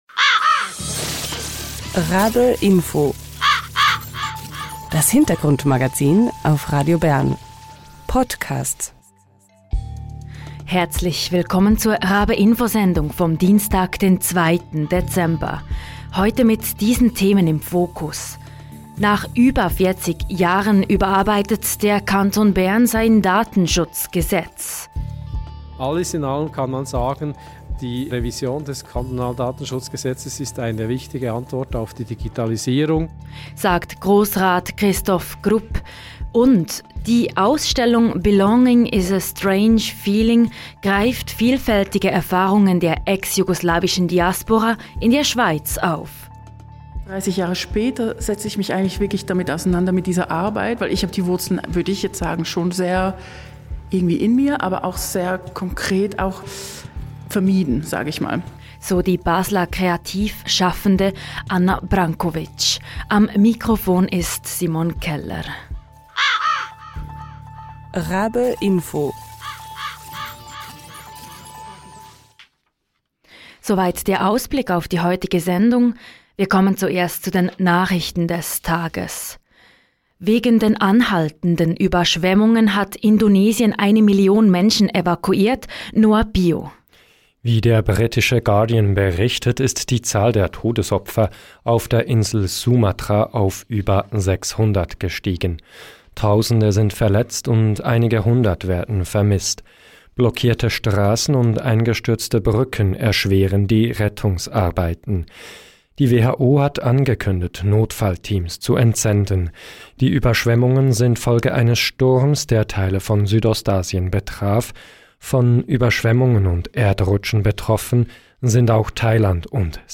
Im Interview erklärt er, worum es dabei genau geht.